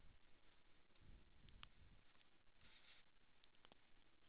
osa 0918 (Monaural AU Sound Data)